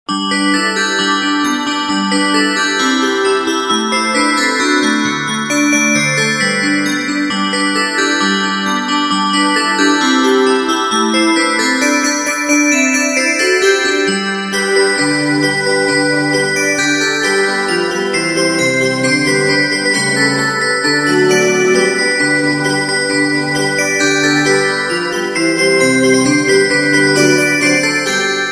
новогодние
Инструментальные рингтоны